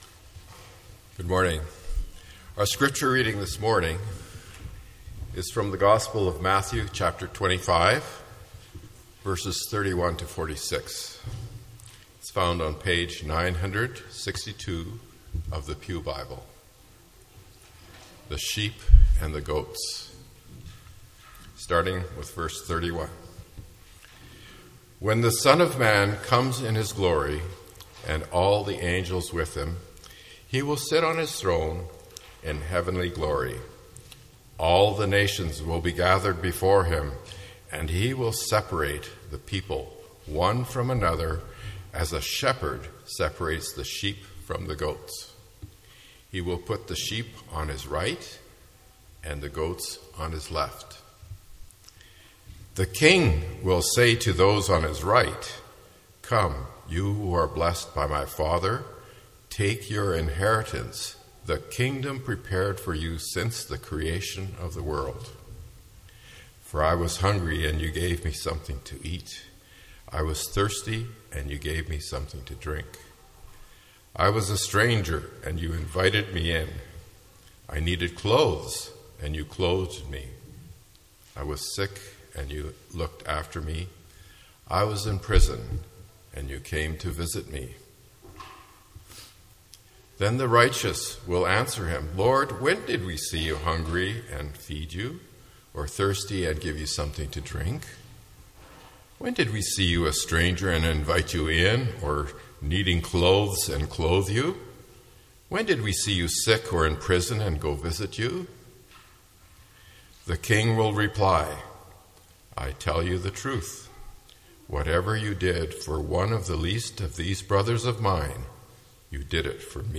Sermon Recordings from Bethesda Church, Winnipeg, Manitoba, CANADA